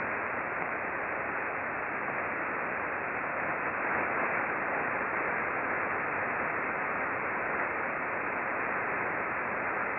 We used two Icom R-75 HF Receivers, one tuned to 19.6 MHz (LSB), corresponding to the Red trace in the charts below, and the other tuned to 20.6 MHz (LSB), corresponding to the Green trace.
The antenna was an 8-element log periodic antenna pointed about 96 degrees true (no tracking was used).
We observed mostly L-bursts.
Most bursts were fairly weak, but there was one very strong burst at about 0239:50 that exceeded 2500k K (see below).
Click here to listen to a mono sound file corresponding to the bursting period associated with the red trace in the chart above.